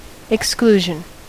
Ääntäminen
Ääntäminen US : IPA : [[ɪkˈskluː.ʒən] Haettu sana löytyi näillä lähdekielillä: englanti Määritelmät Substantiivit The act of excluding or shutting out; removal from consideration or taking part.